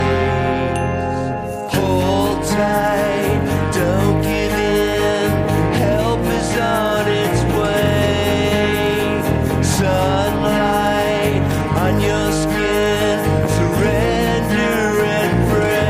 A healthy handful of chords, tautly played.